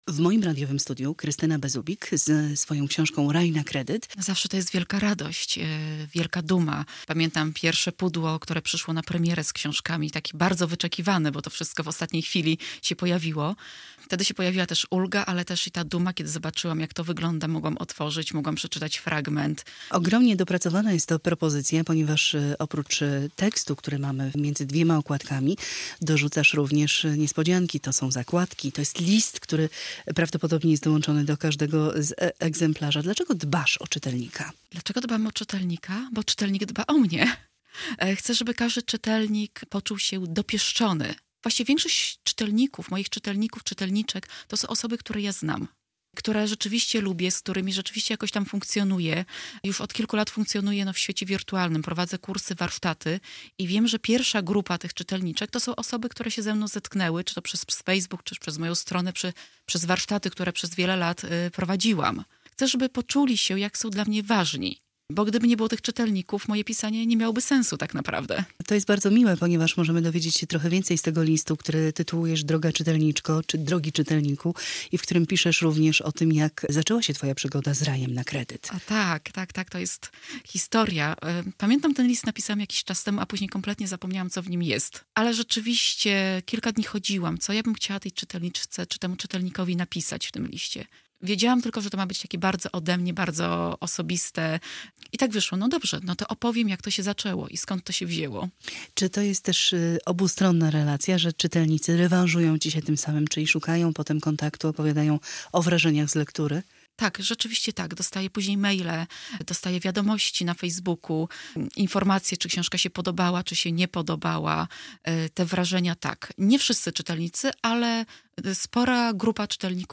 Z autorką rozmawia